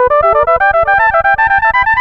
Synth 14.wav